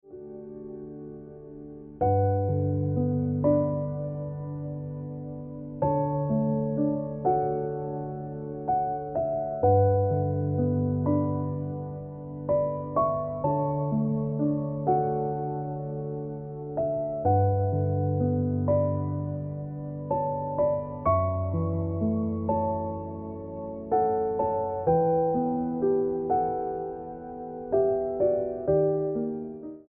Best Ringtones, Piano Music Ringtone